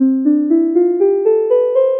これは、ピアノに似た電子音で「ドレミファソラシド」を演奏した時の スペクトル図です。
サンプル波形データ